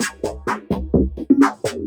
Index of /VEE/VEE2 Loops 128BPM
VEE2 Electro Loop 378.wav